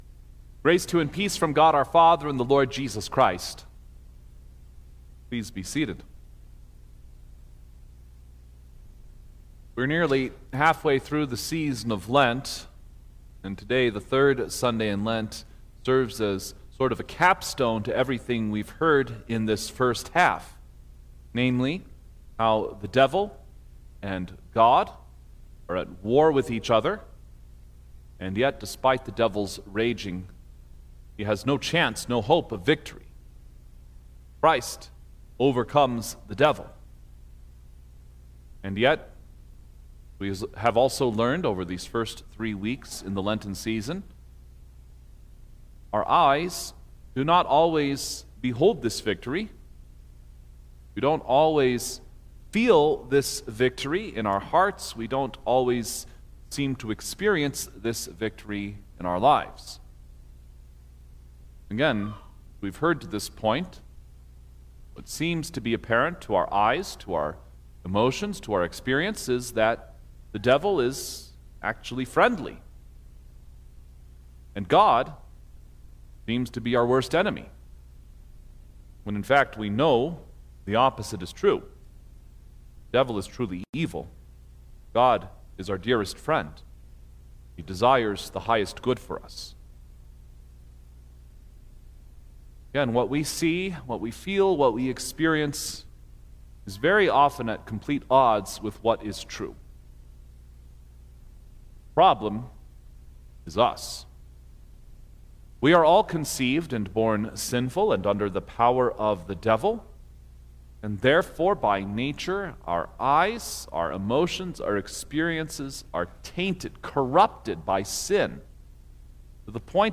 March-8_2026_Third-Sunday-in-Lent_Sermon-Stereo.mp3